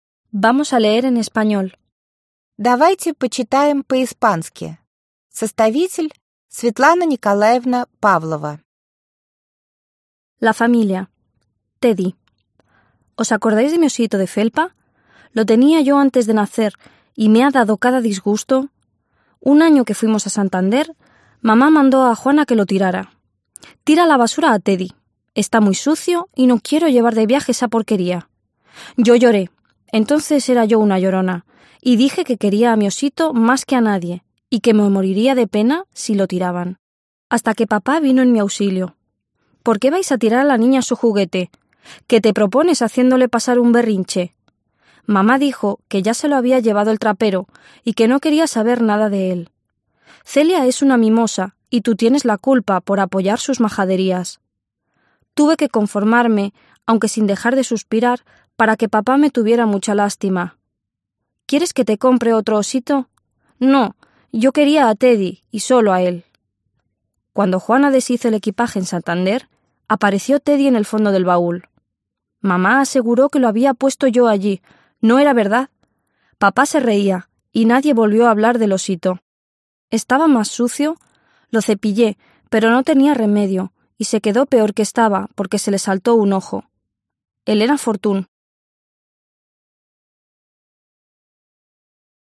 Аудиокнига Давайте почитаем по-испански!